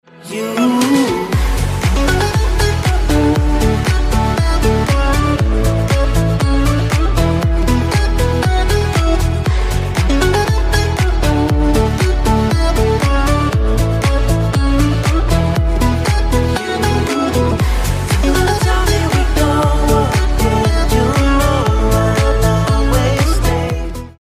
• Качество: 320, Stereo
поп
мужской вокал
dance
EDM
романтичные
house